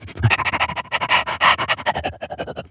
dog_sniff_breathe_01.wav